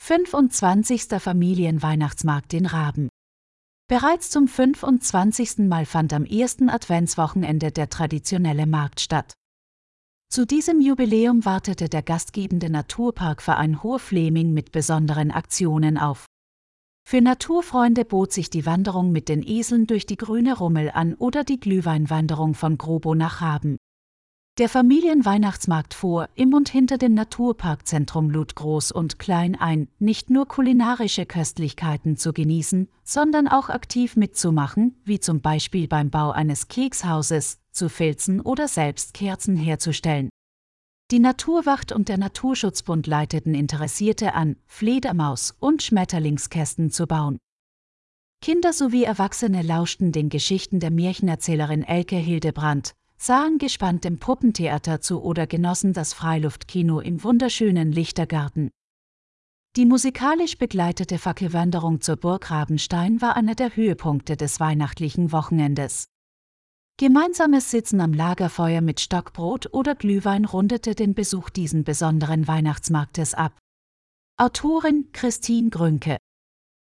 Einige unserer Artikel können Sie sich mit Unterstützung einer Künstlichen Intelligenz auch anhören.